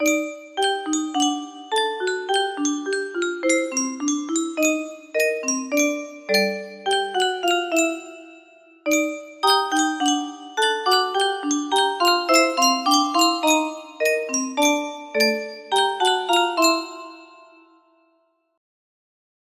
:} music box melody
Full range 60
Silly